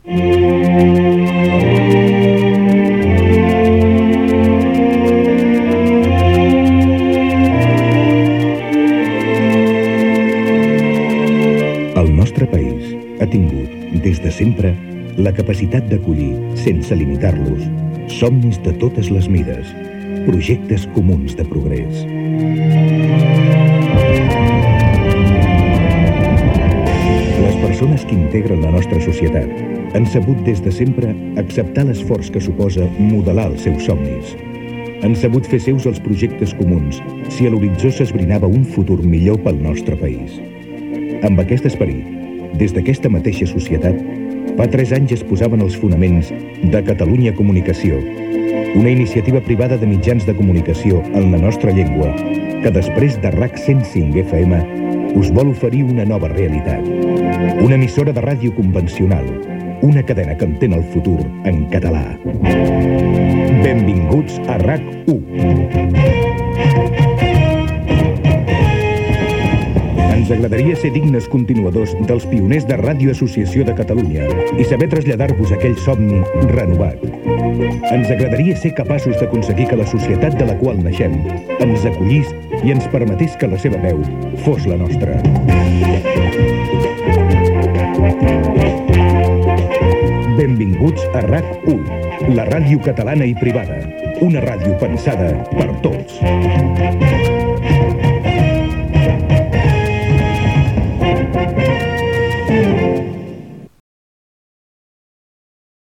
666d1ef39ab8ee9788bd3b783ced0f5e32db8206.mp3 Títol RAC 1 Emissora RAC 1 Barcelona Cadena RAC Titularitat Privada nacional Descripció Falca promocional de benvinguda el dia de la inauguració oficial de l'emissora.